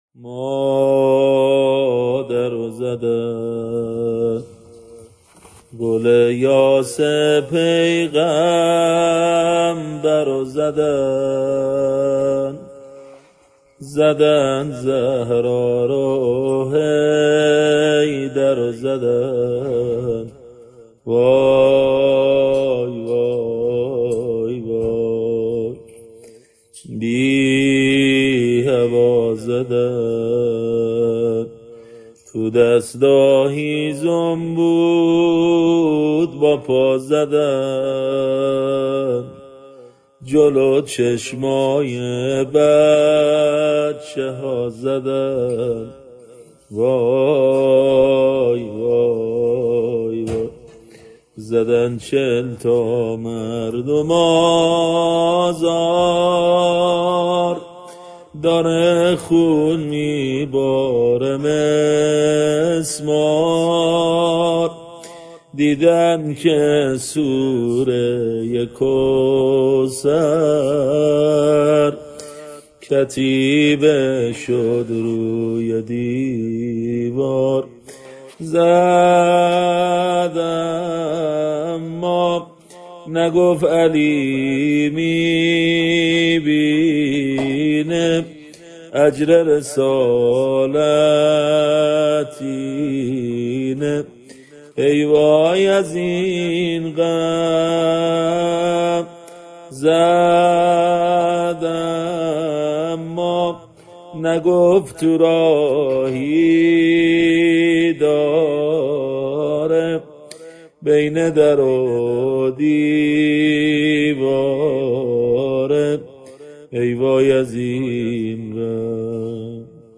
سبک_مادر_و_زدن